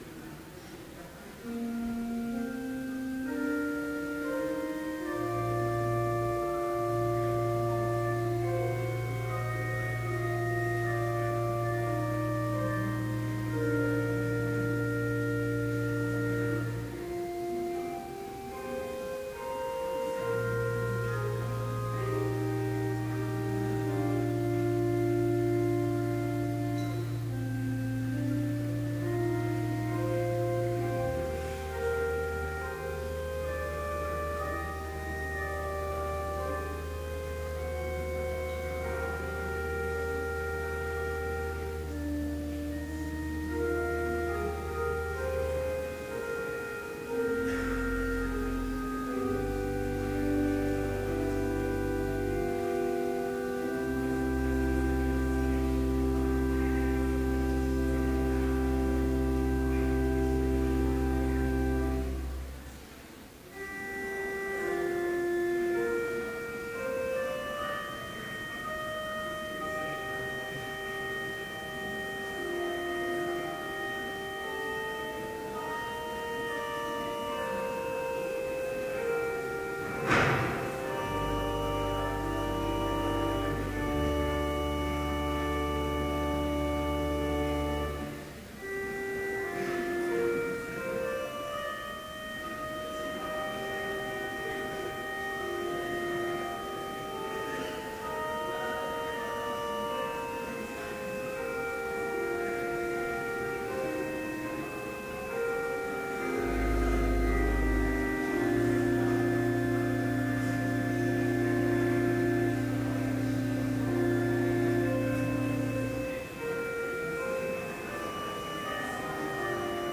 Complete service audio for Chapel - February 26, 2013